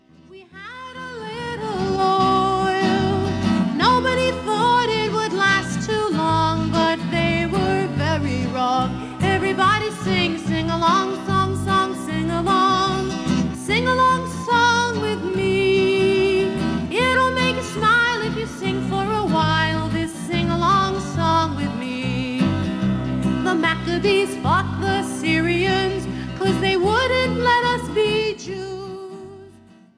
A Jewish sing-a-long for families!